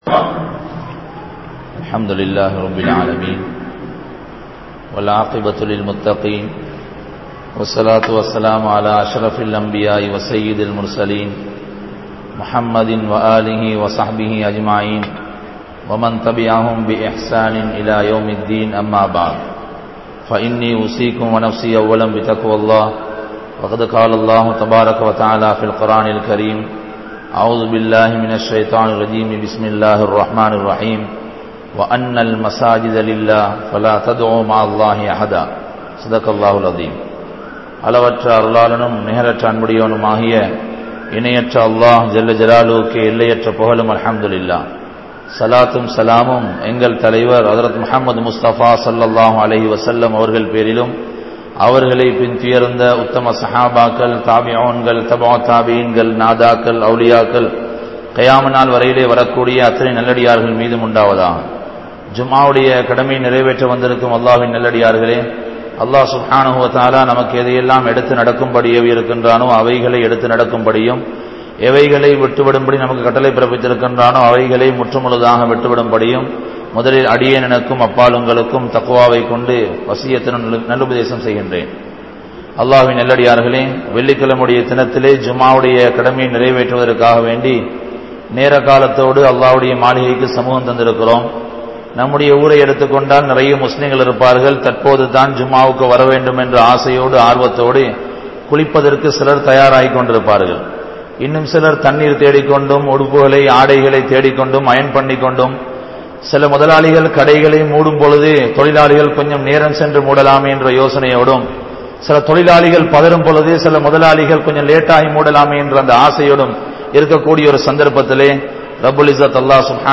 Barakath(Blessed) | Audio Bayans | All Ceylon Muslim Youth Community | Addalaichenai